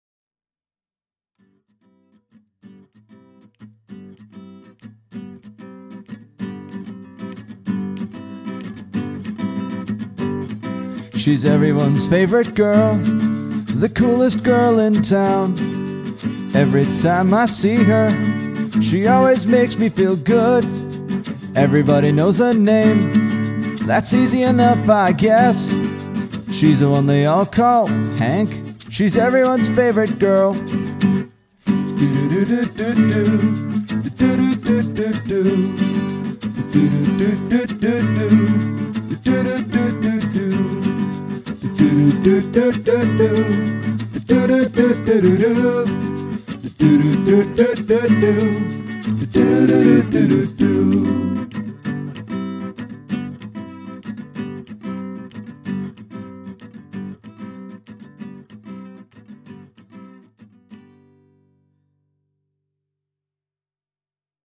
Written (in about ten minutes) and recorded in 2007, this track is highlighted by a cheap joke and a quick batch of vocal harmony.
Production, mixing, all instruments by me.